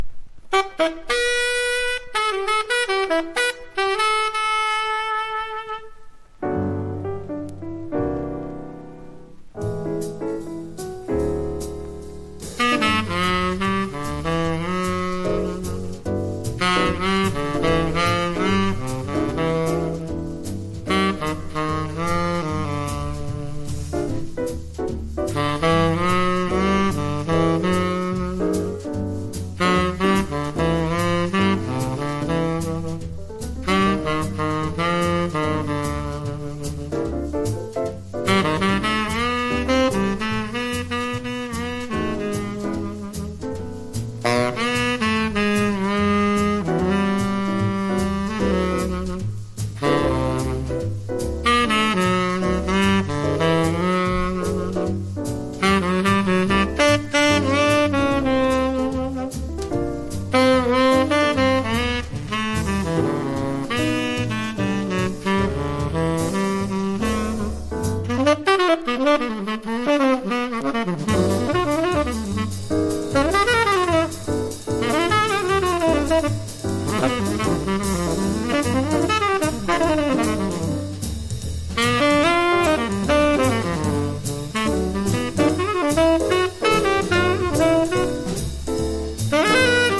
流石、彼のサックスは一味違います。ゆったりスウィングしたドラムも心地良いです。